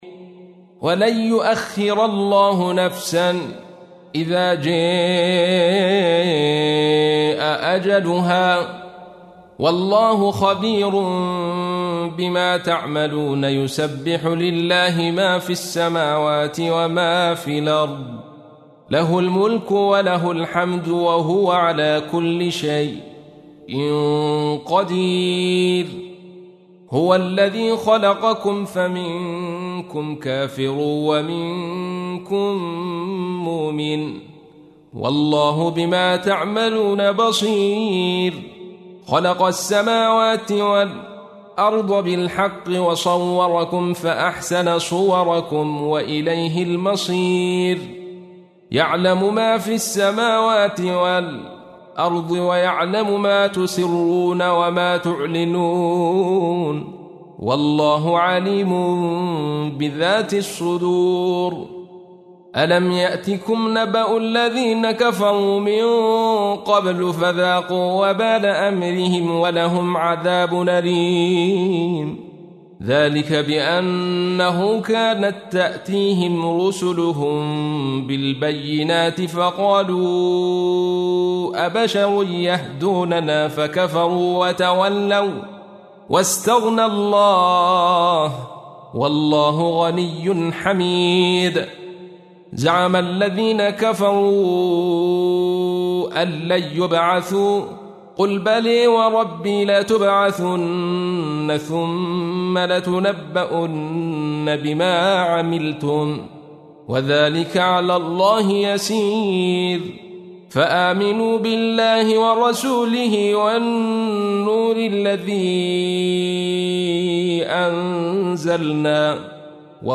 تحميل : 64. سورة التغابن / القارئ عبد الرشيد صوفي / القرآن الكريم / موقع يا حسين